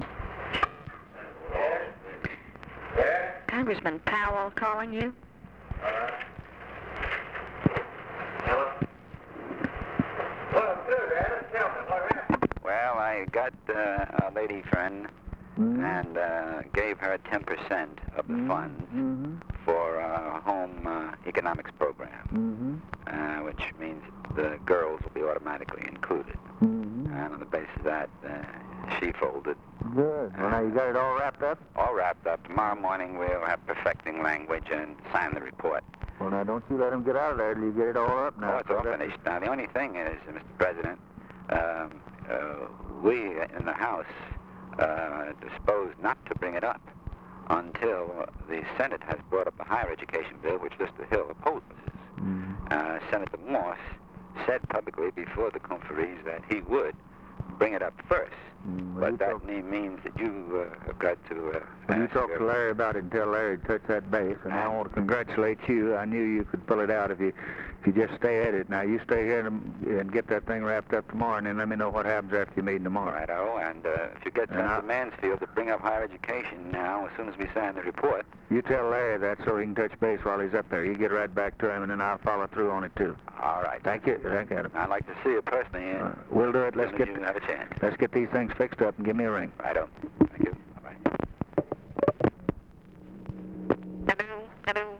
Conversation with ADAM CLAYTON POWELL, December 9, 1963
Secret White House Tapes